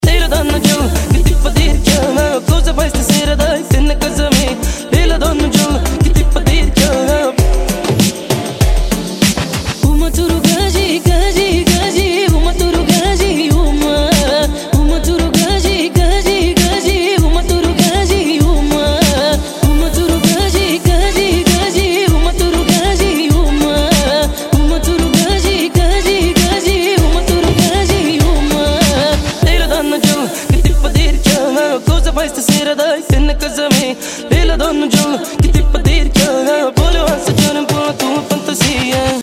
• Качество: 160, Stereo